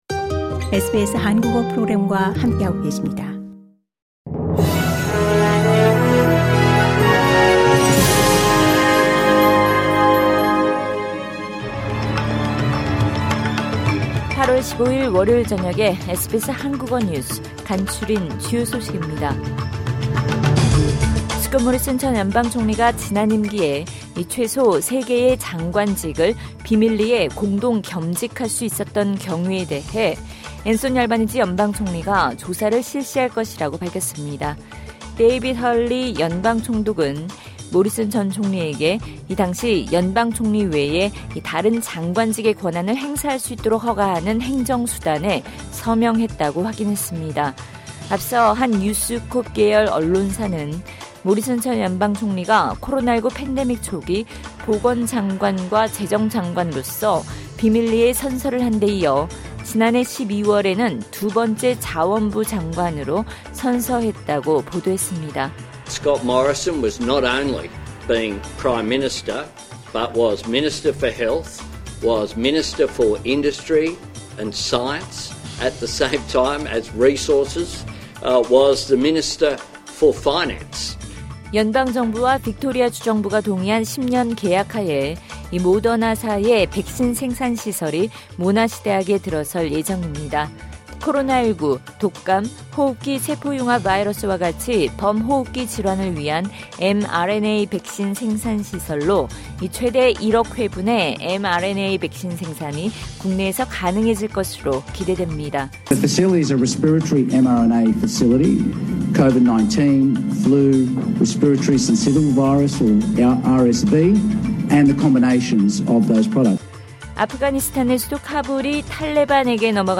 2022년 8월 15일 월요일 저녁 SBS 한국어 간추린 주요 뉴스입니다.